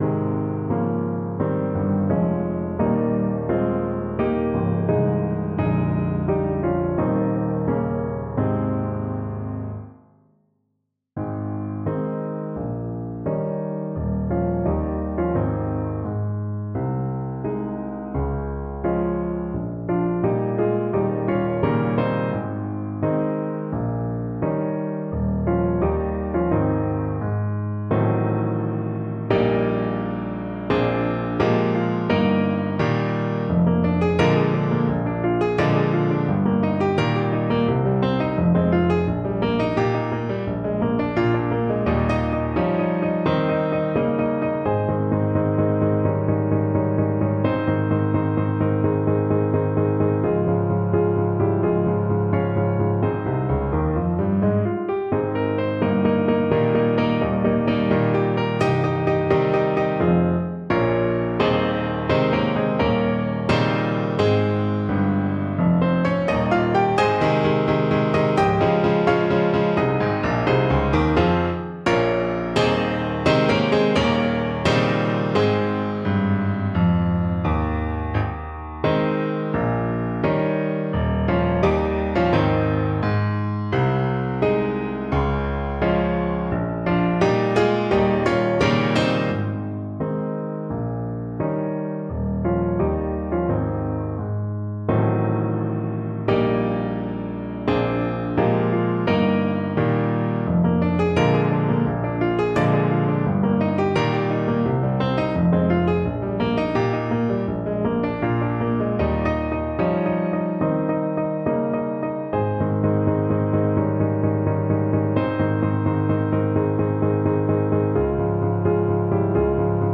Free Sheet music for Alto Saxophone
Alto Saxophone
4/4 (View more 4/4 Music)
Classical (View more Classical Saxophone Music)